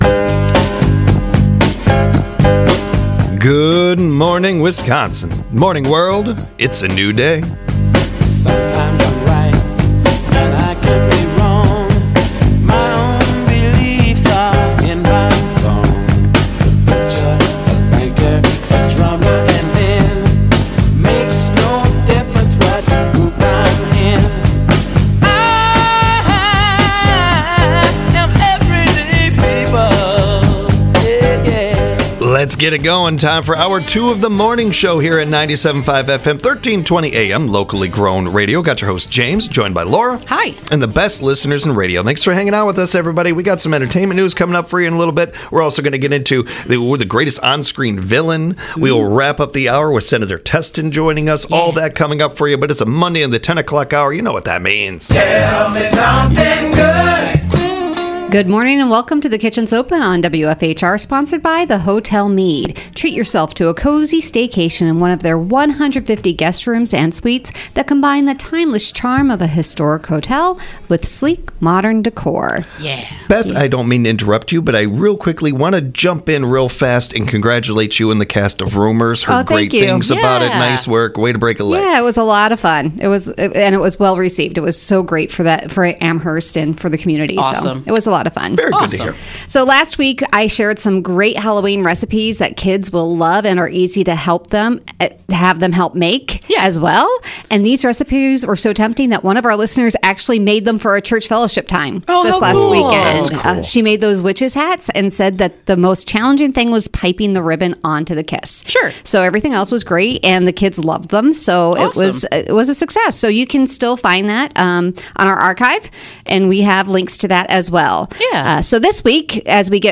Then they explore a list of the scariest horror villains . They wrap things up with Senator Patrick Testin as he visits the studios for an edition of his “ On the Job ” series.